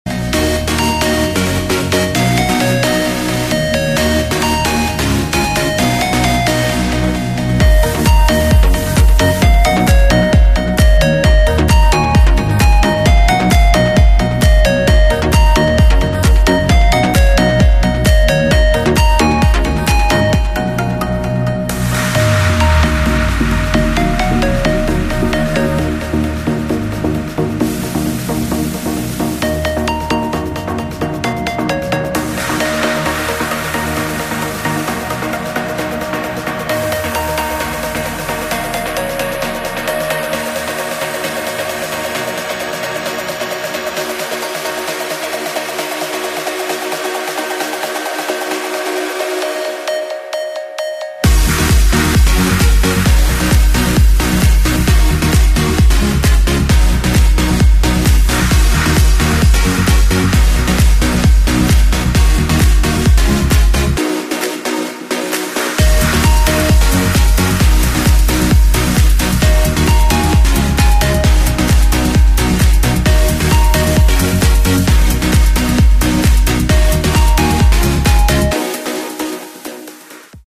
• Качество: 128, Stereo
громкие
dance
Electronic
EDM
электронная музыка
без слов
club
Trance